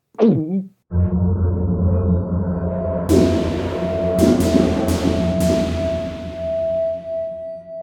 drink potion sound.
potion.ogg